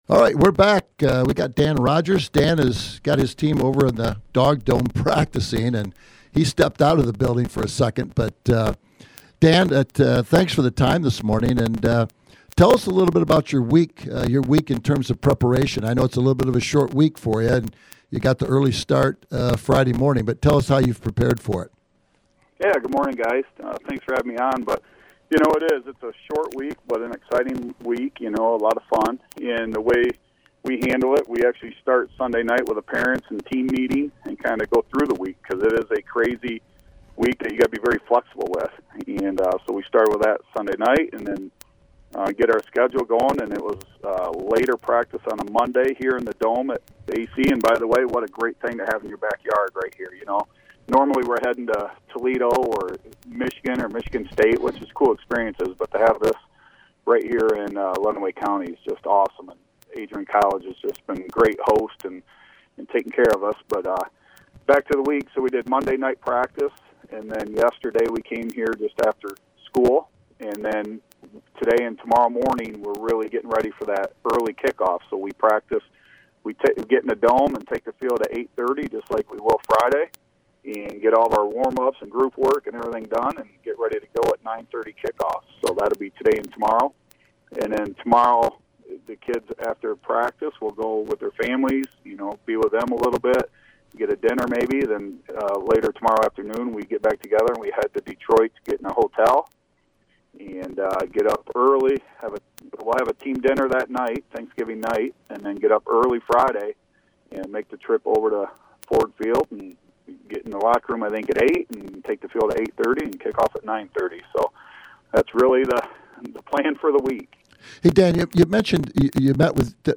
Here is the full interview: